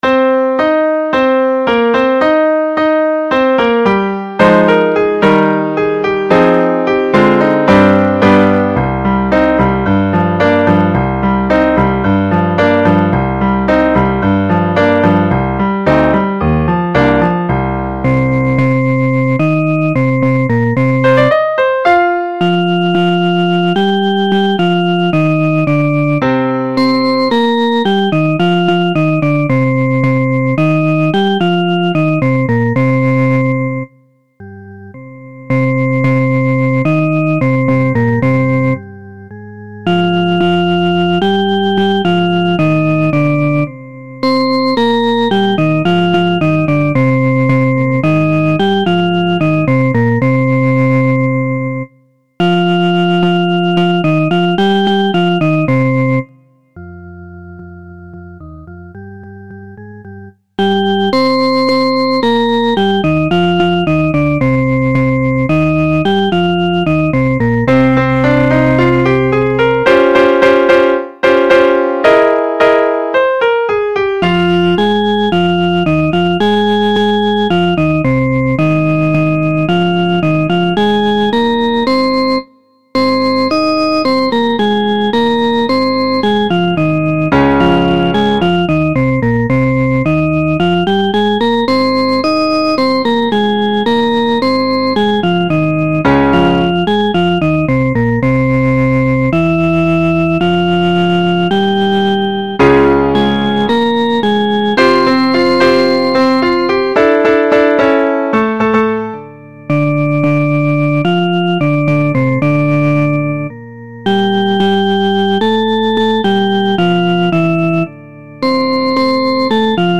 Prepare_Make_Room_04_Soprano.MP3